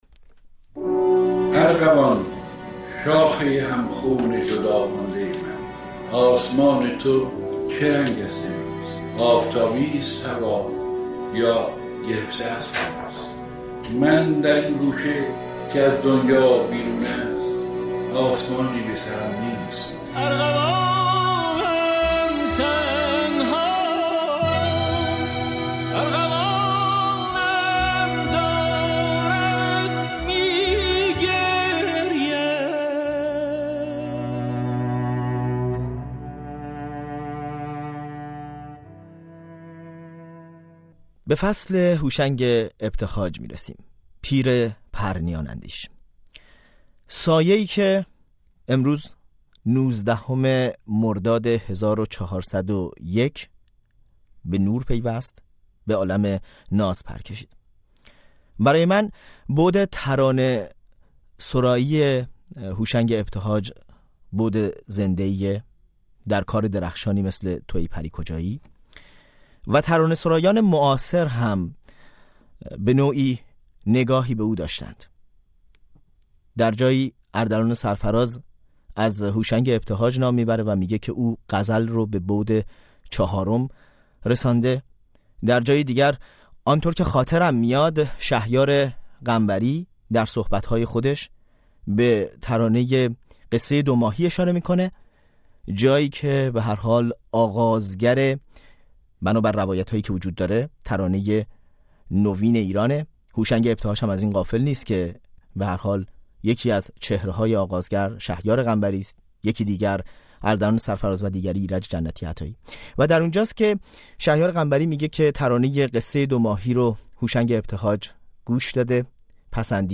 همراه با گفت‌وگویی قدیمی با شاعر شعر ارغوان.